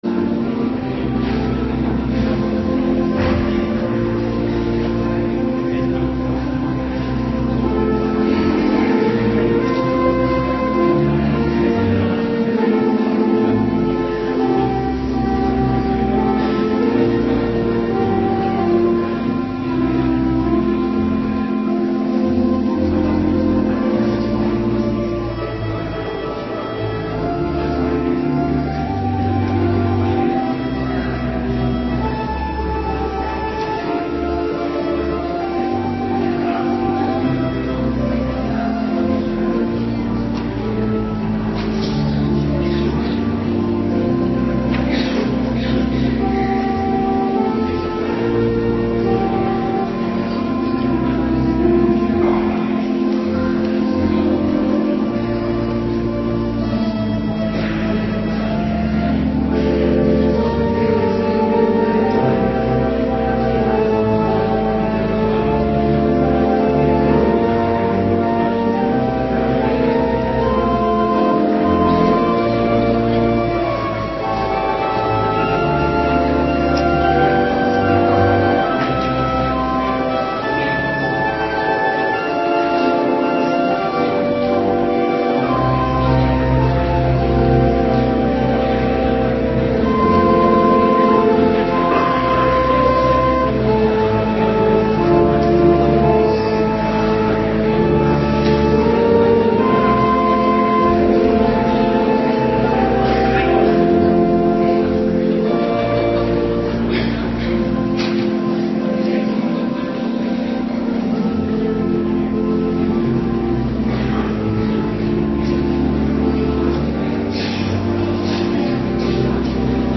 Morgendienst 4 januari 2026